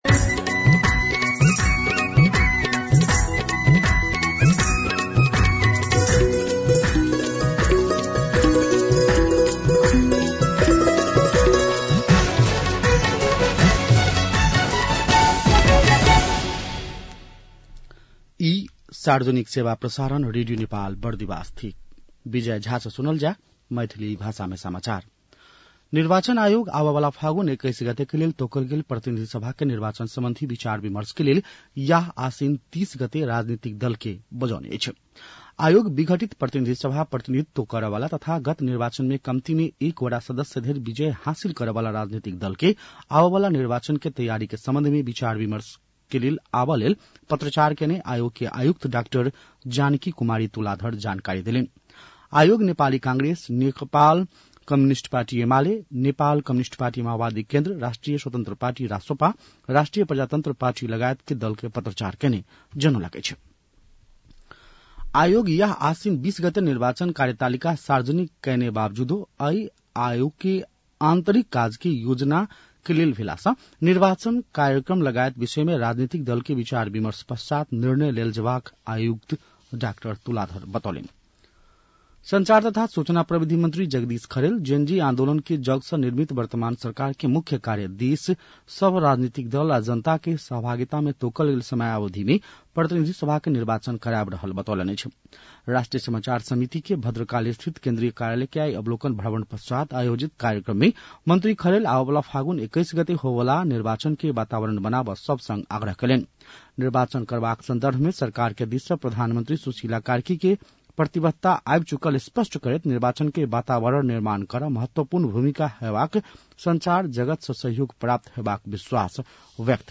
मैथिली भाषामा समाचार : २२ असोज , २०८२